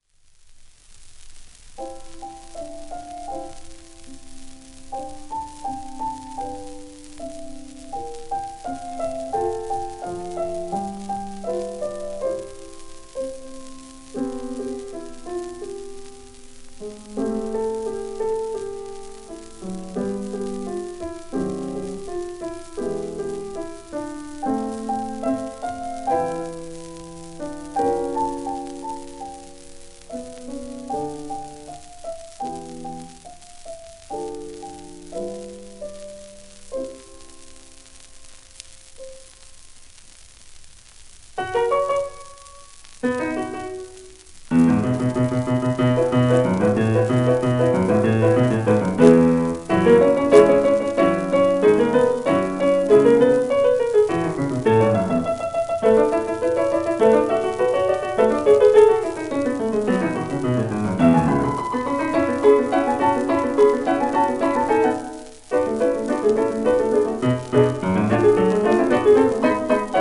スウェーデンを代表するピアニストで作曲家